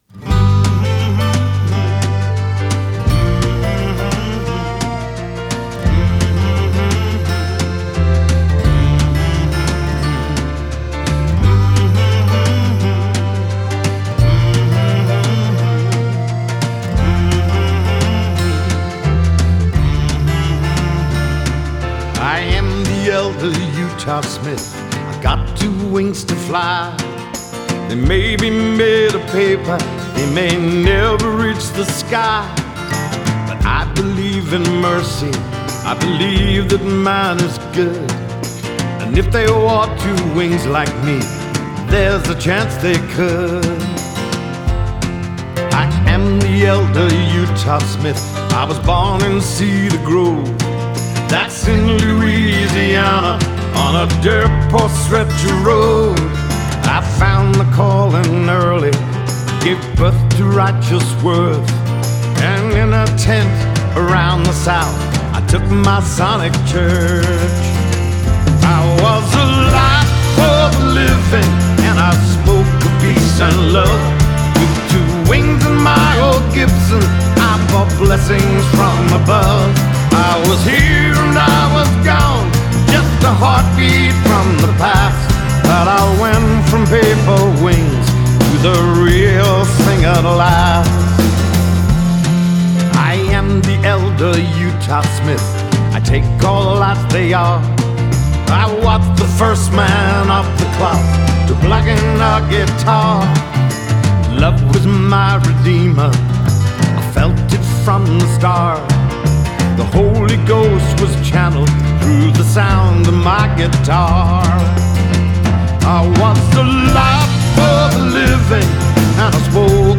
recorded at The Village in Los Angeles
Genre: Pop Rock, Classic Rock, Soft Rock